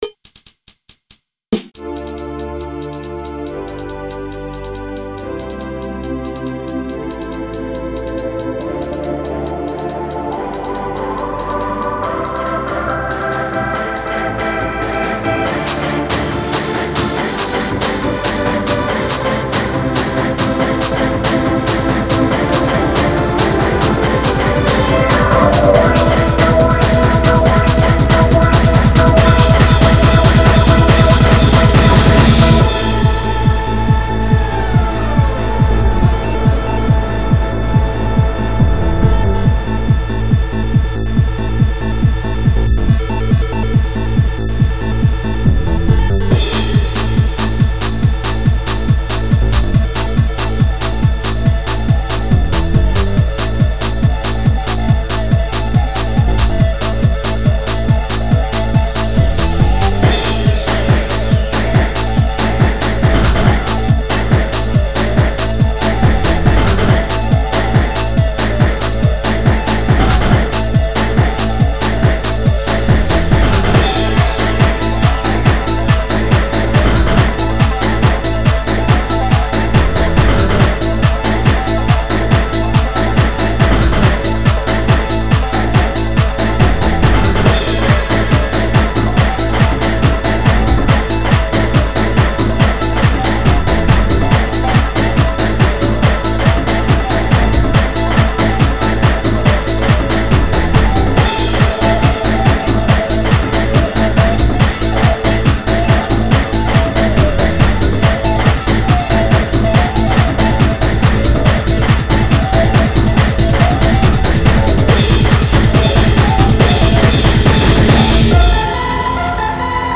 Thechno-trance